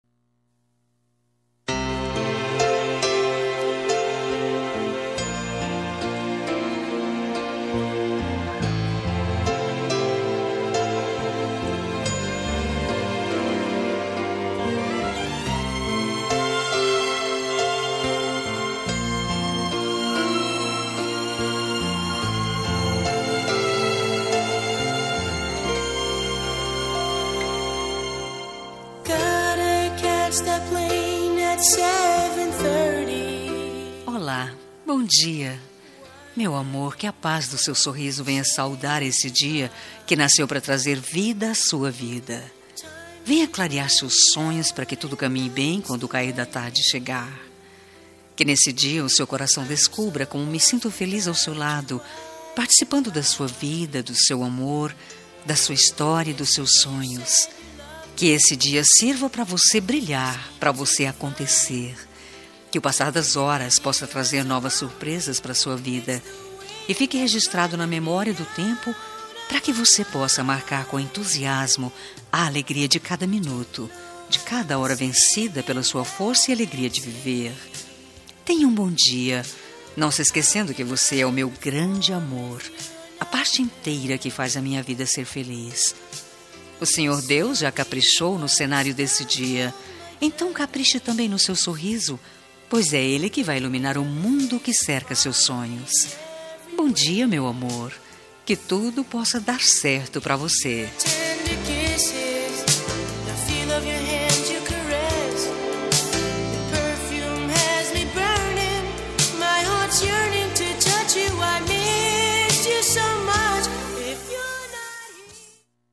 Telemensagem de Bom dia – Voz Feminina – Cód: 6314 – Romântica
6314-dia-fem-romant.mp3